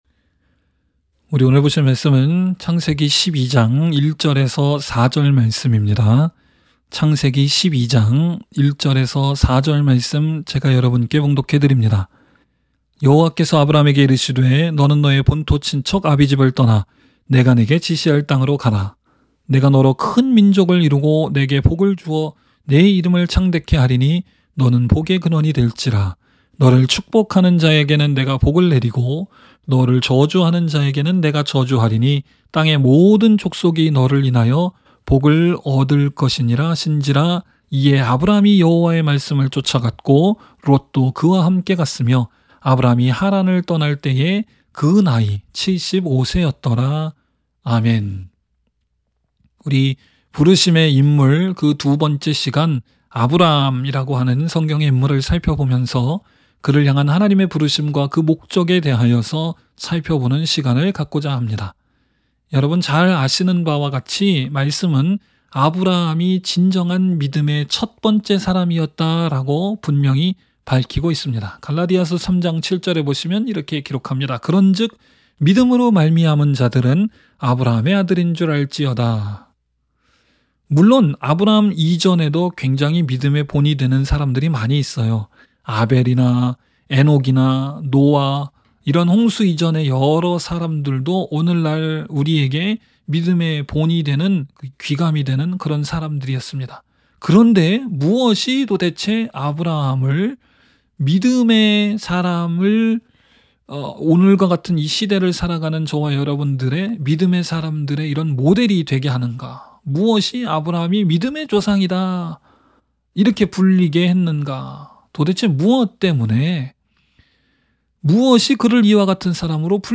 by admin-new | Feb 21, 2022 | 설교 | 0 comments